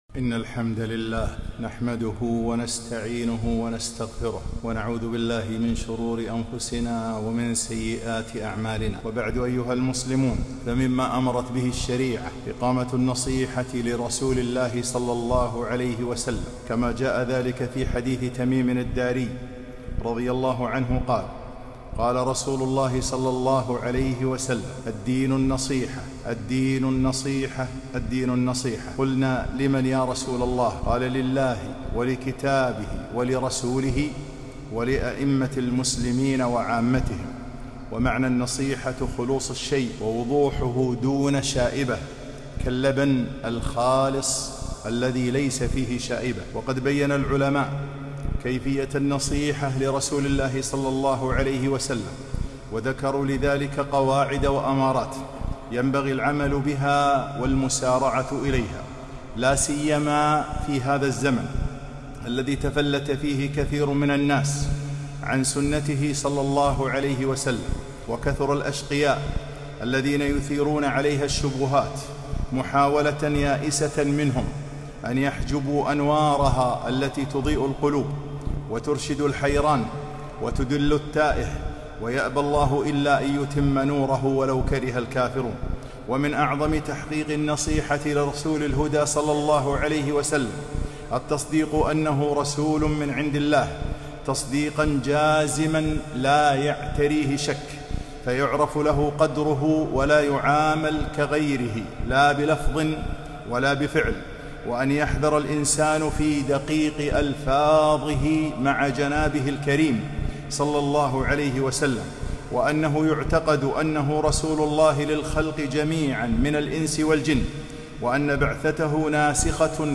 خطبة - من حقوق النبي ﷺ